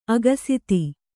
♪ agasiti